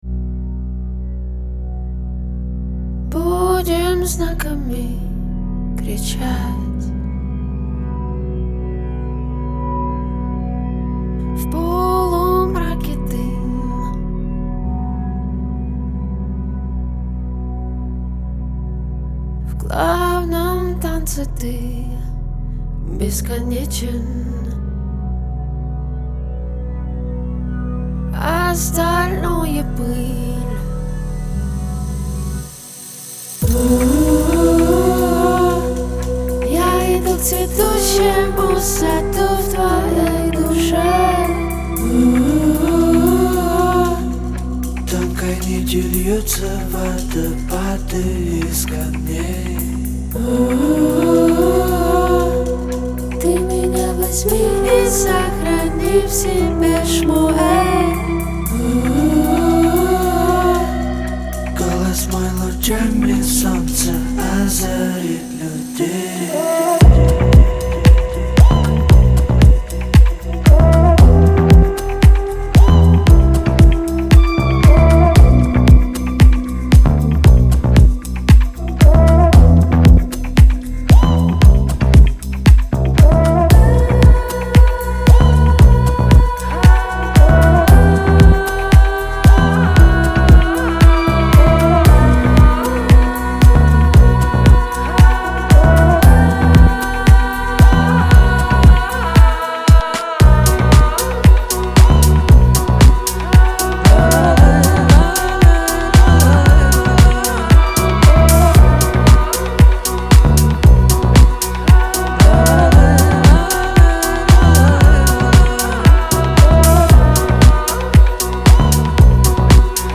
Жанр: electronics